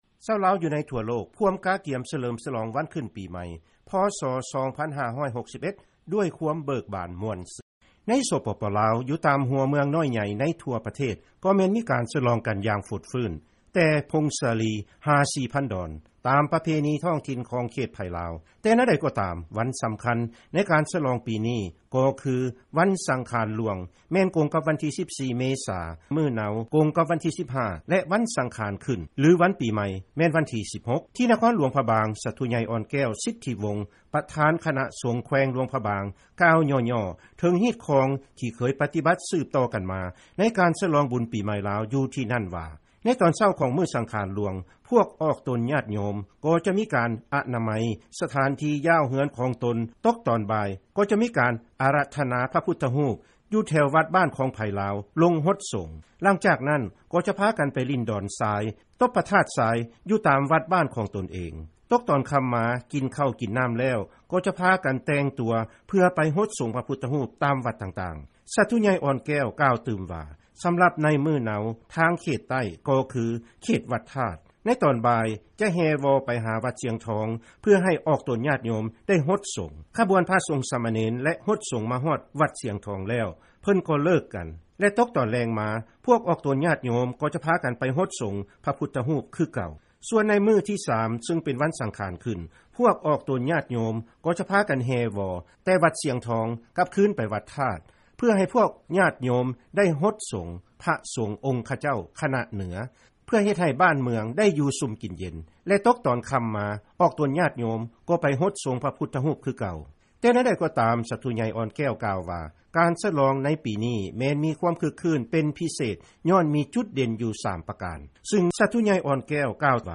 ເຊີນຟັງລາຍງານ ບຸນປີໃໝ່ລາວ ປີຈໍ ພ.ສ 2561